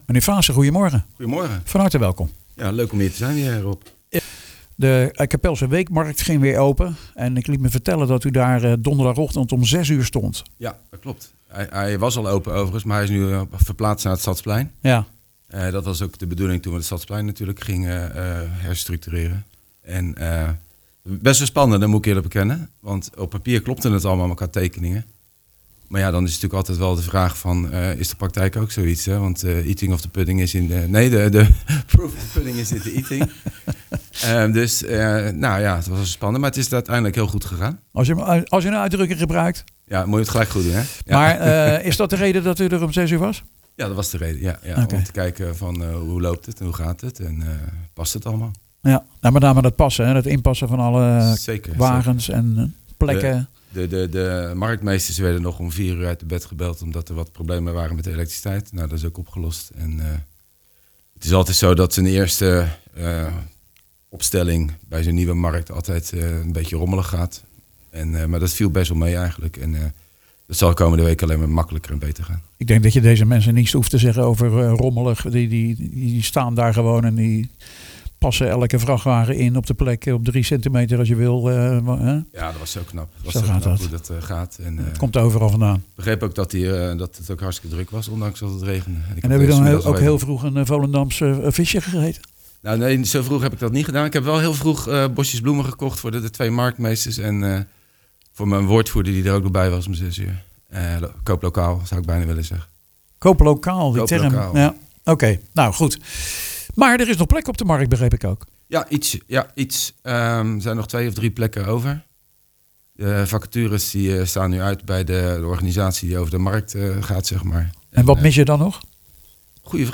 praat erover met wethouder Eric Faassen